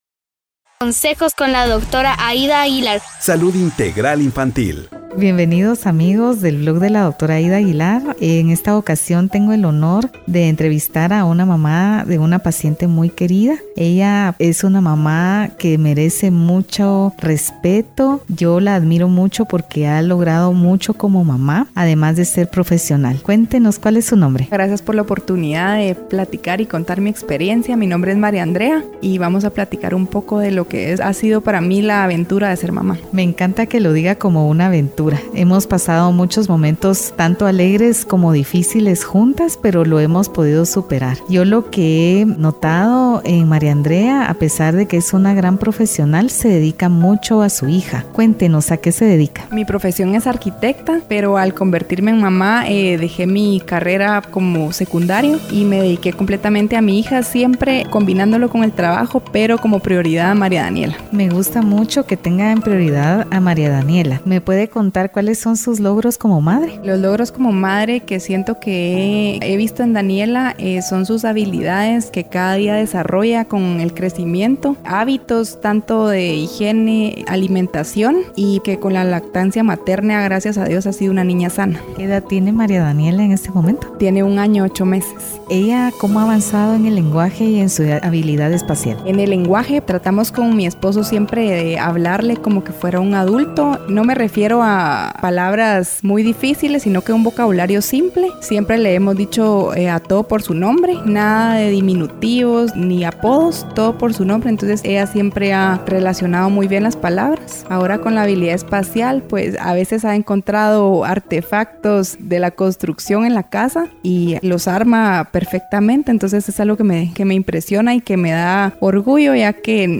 Celebramos a las ¡Súper Mamás! Entrevista 3 Podcast #20